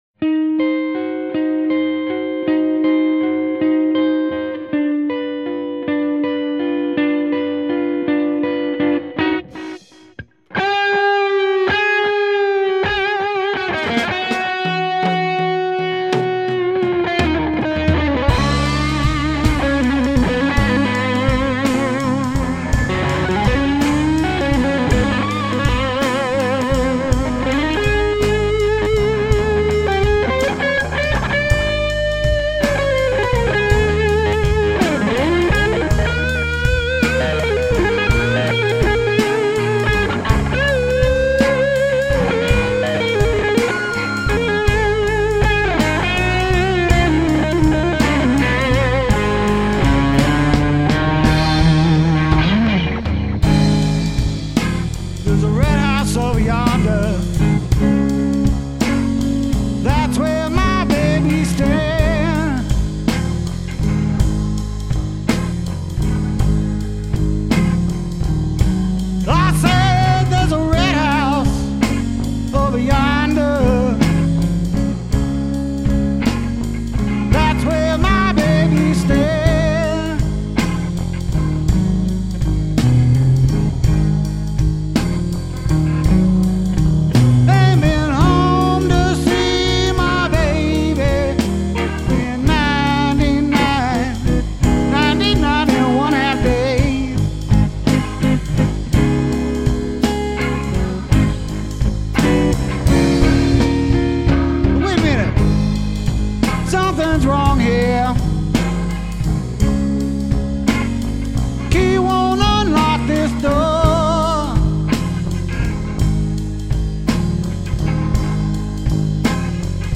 Vocals and Drums
Guitar Keyboard Pedal